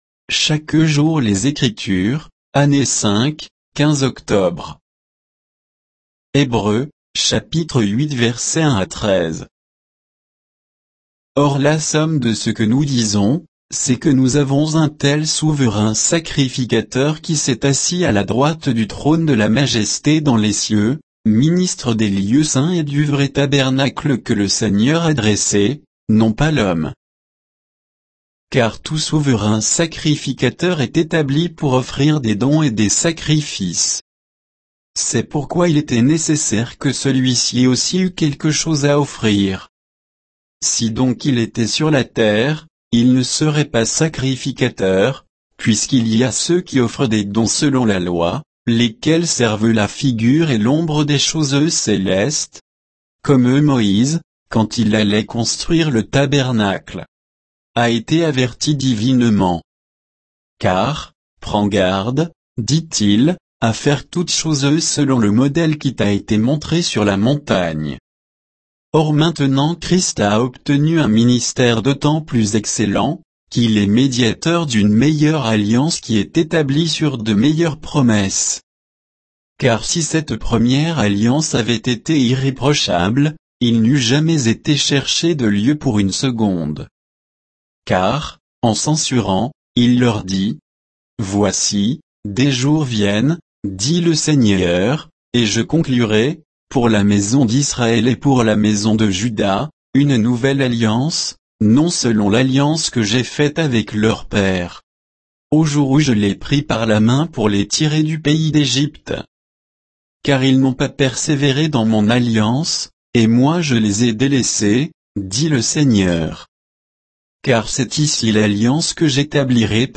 Méditation quoditienne de Chaque jour les Écritures sur Hébreux 8